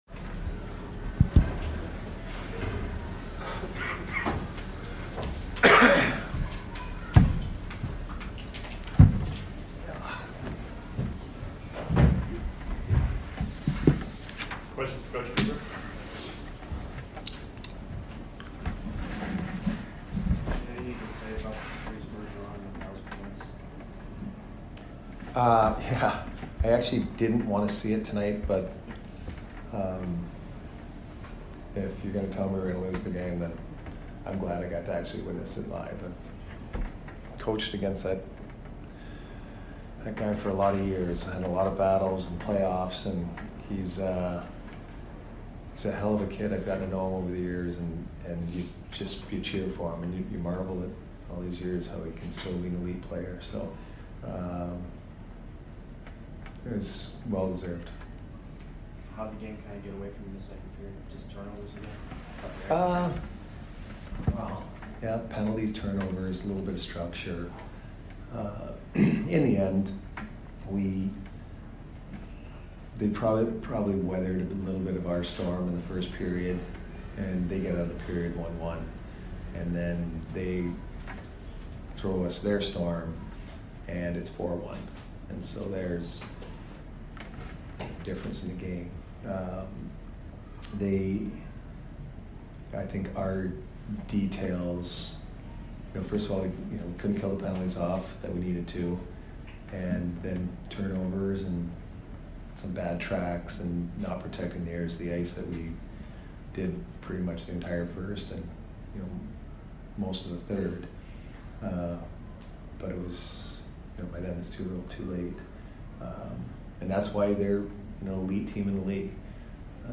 Head Coach Jon Cooper Post Game 11/21/22 vs. BOS